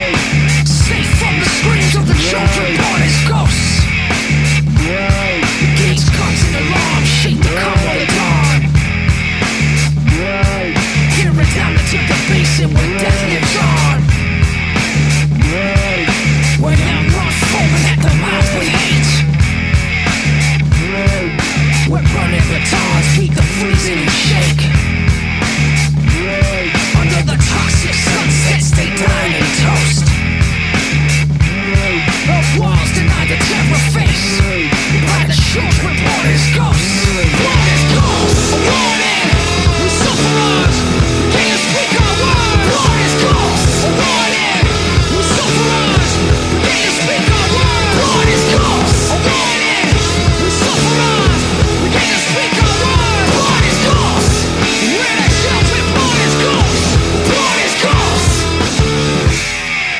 Рок